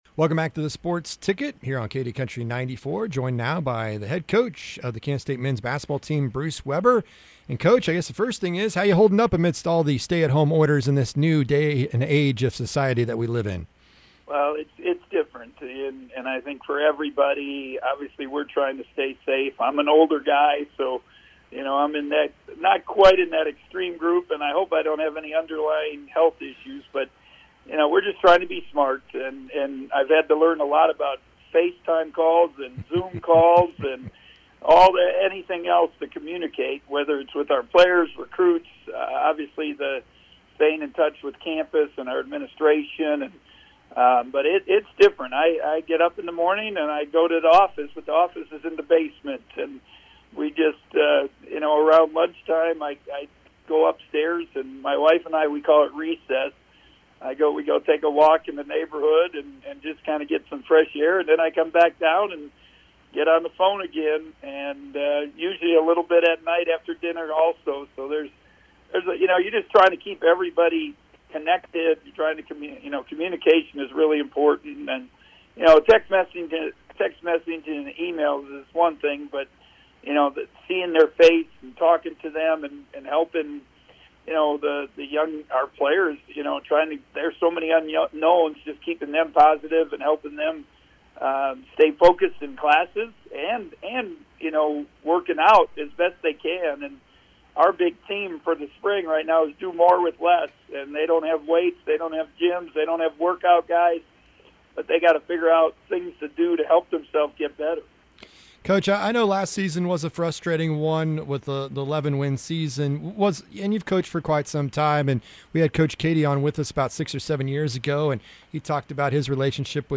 K-State men's basketball coach Bruce Weber joins us to recap the 2019-20 season and for a look at the 2020-21 recruiting class and the future.